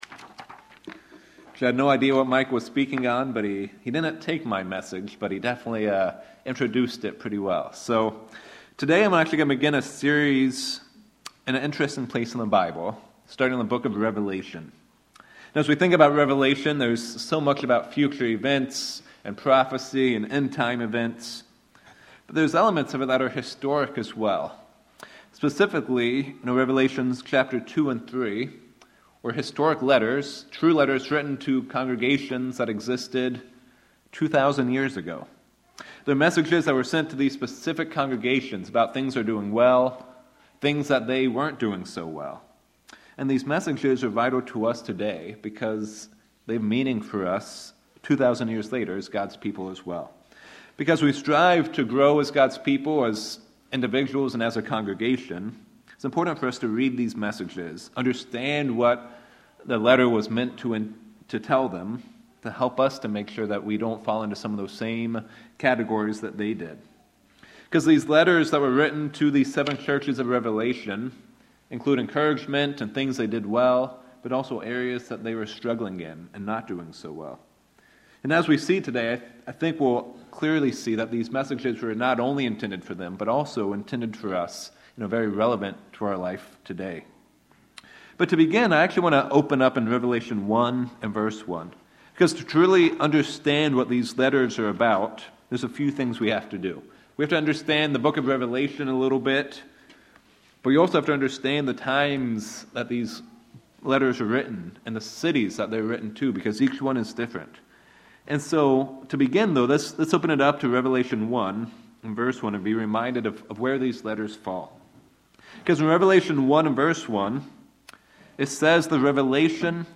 Sermons
Given in Sacramento, CA Reno, NV